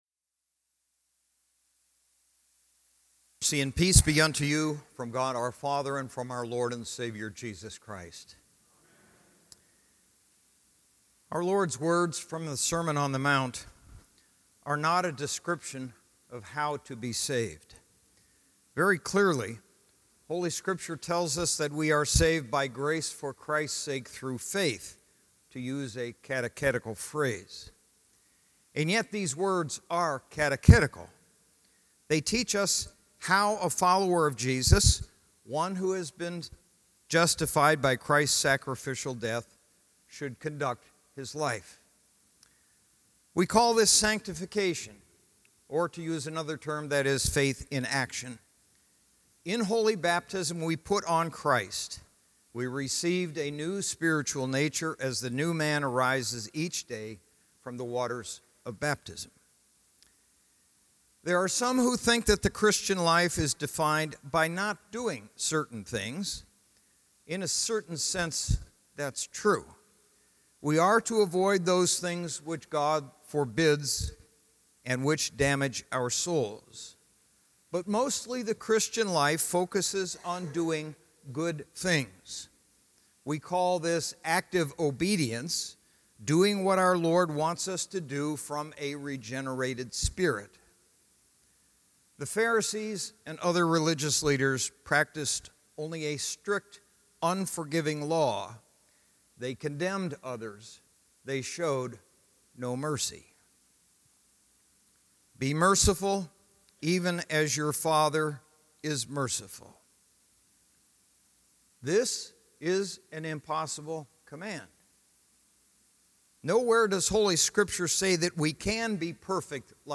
Fourth Sunday after Trinity